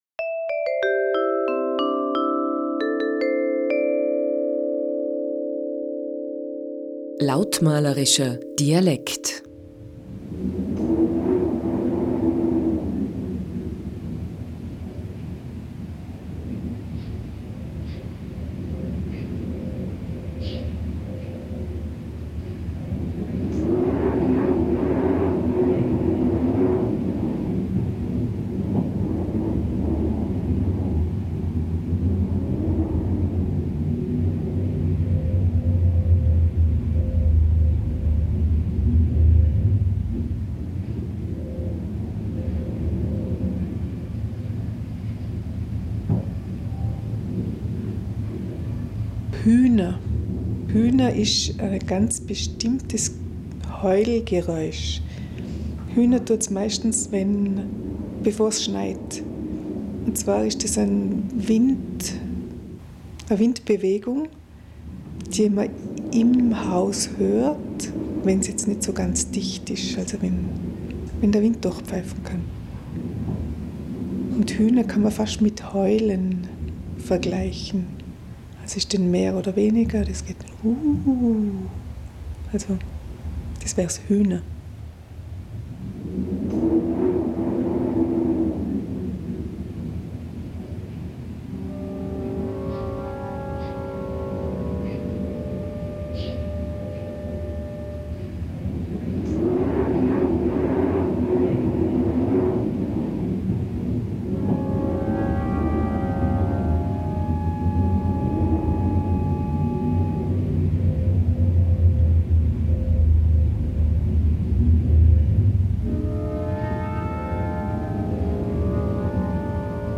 Menschen aus Lech erzählen, welche Geräusche sie in ihrem Alltag begleiten und welche Töne in ihrer Biografie eine Rolle gespielt haben.
Ihre Erzählungen und die damit assoziierten Geräusche verbinden sich mit den eigens dazu komponierten und eingespielten Musikfragmenten zu neun sehr unterschiedlichen Klangreisen in vergangene und gegenwärtige Welten.